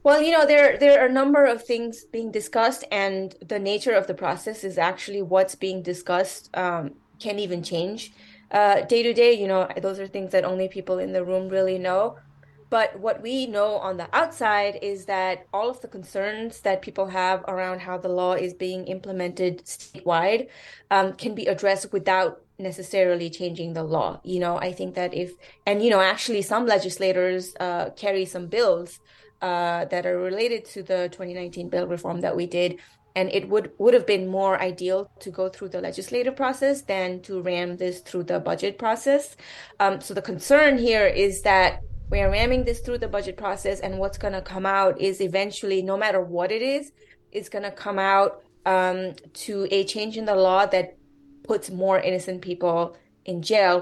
Interviewed on WGXC April 12, Ulster County Assemblymember Sarahana Shrestha said there is little information available about how bail reform might change.